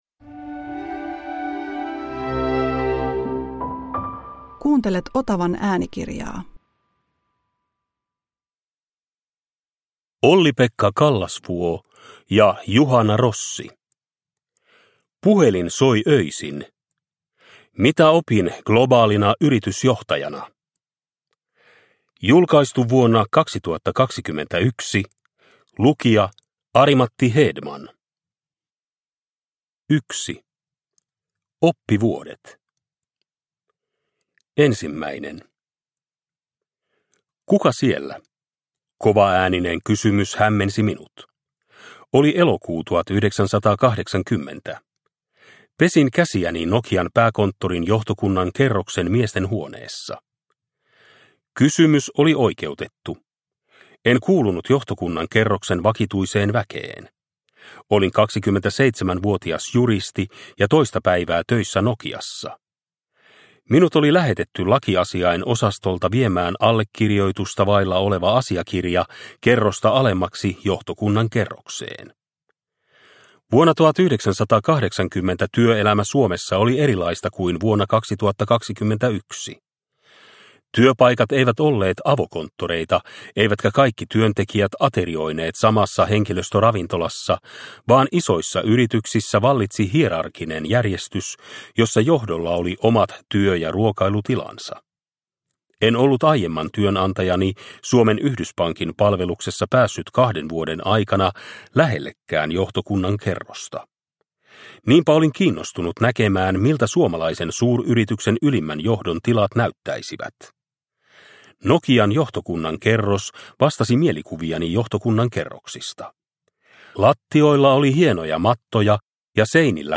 Puhelin soi öisin – Ljudbok – Laddas ner